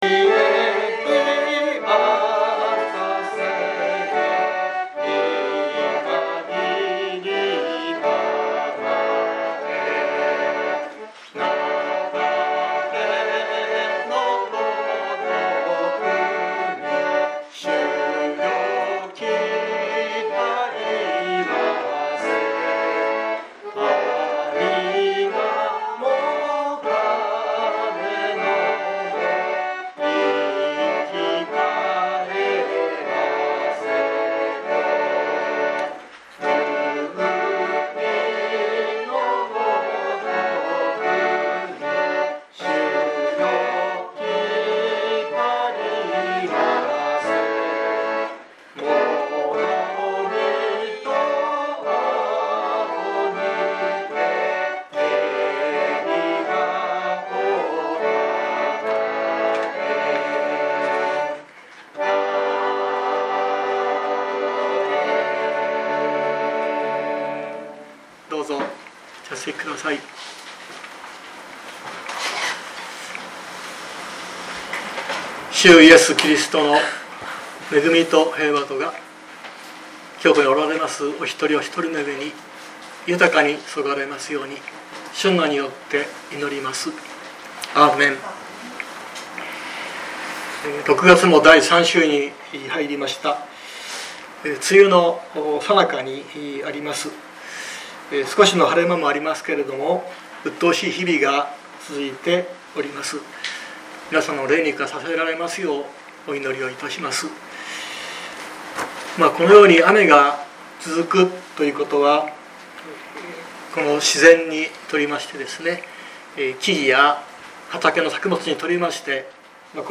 熊本教会。説教アーカイブ。